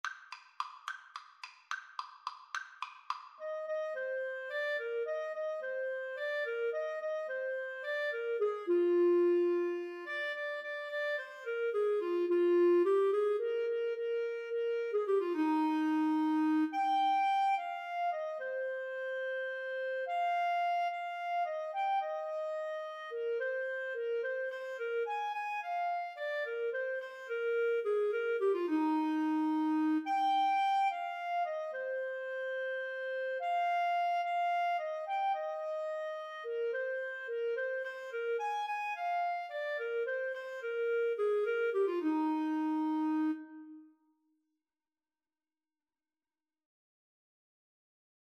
Free Sheet music for Clarinet-Saxophone Duet
ClarinetAlto Saxophone
Eb major (Sounding Pitch) F major (Clarinet in Bb) (View more Eb major Music for Clarinet-Saxophone Duet )
3/4 (View more 3/4 Music)
Classical (View more Classical Clarinet-Saxophone Duet Music)